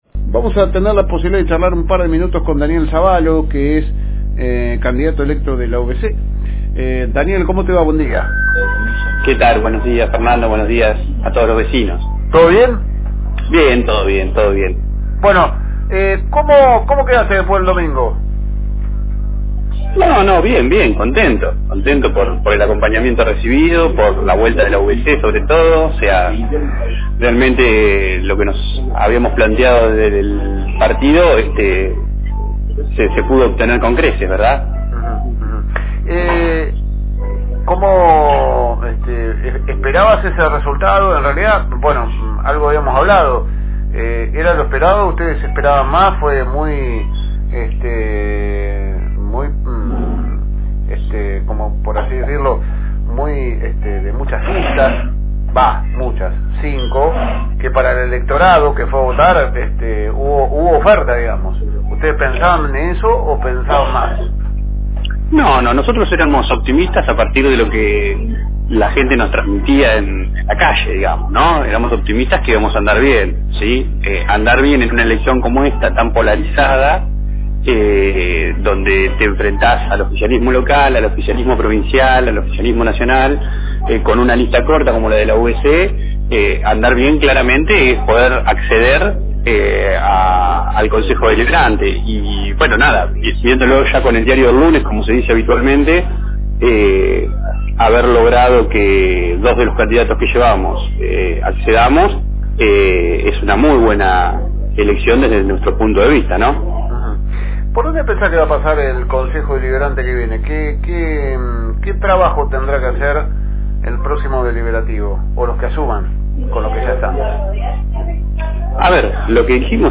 En Esto es Noticia charlamos con Daniel Zabalo, que desde diciembre ocupara su banca en el Honorable Concejo Deliberante (HCD) luego de salir terceros en las elecciones del domingo con su lista de la Unión Vecinal Conservador (UVC).